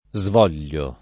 vai all'elenco alfabetico delle voci ingrandisci il carattere 100% rimpicciolisci il carattere stampa invia tramite posta elettronica codividi su Facebook svolere [ @ vol % re ] v.; svoglio [ @ v 0 l’l’o ], svuoi — coniug. come volere